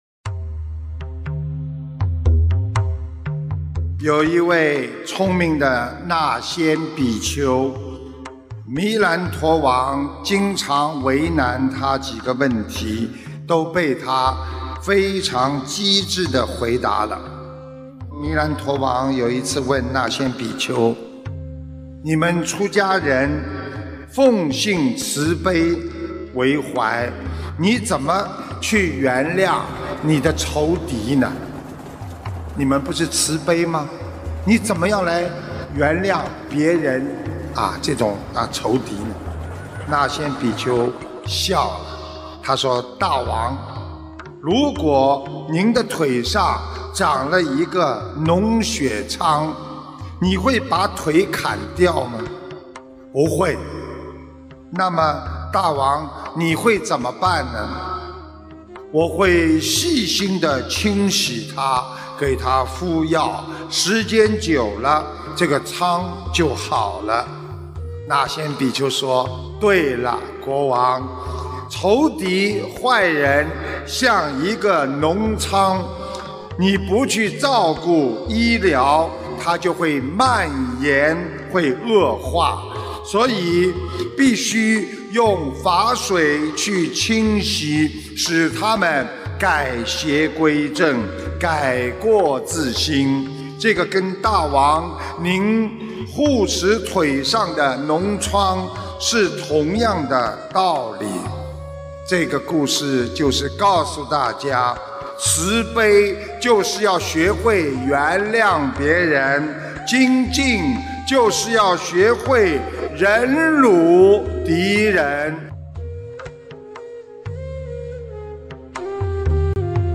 音频：那先比丘的故事 如何原谅你的敌人！马来西亚·吉隆坡世界佛友见面会开示2017年08月25日！